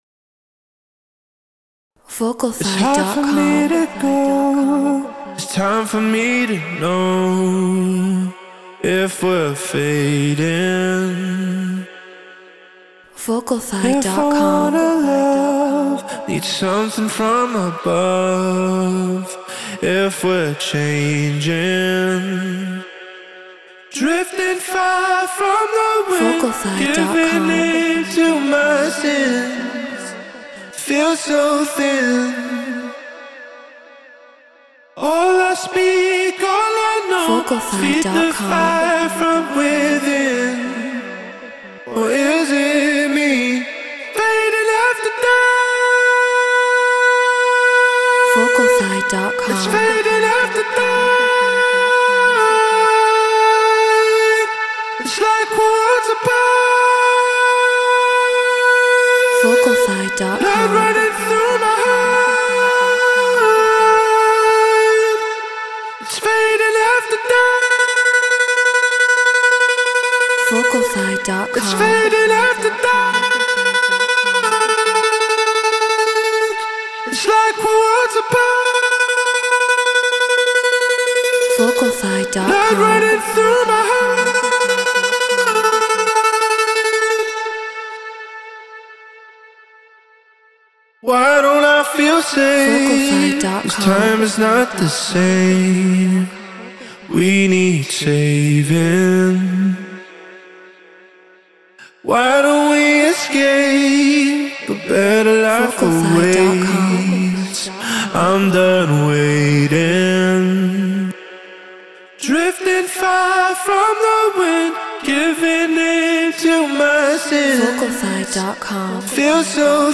Drum & Bass 174 BPM Bmin